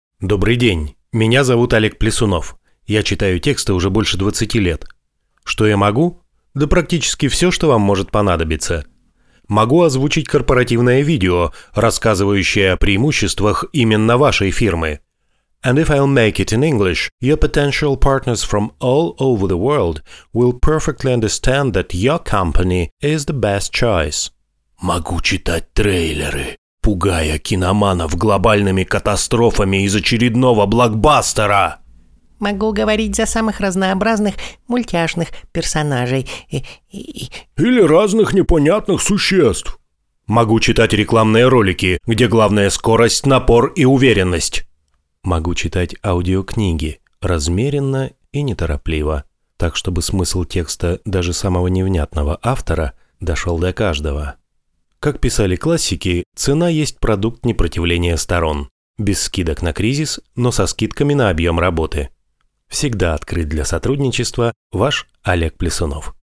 Собственная студия.
Голос разноплановый и мультитембральный.
SE Electronics USB2200a, кабина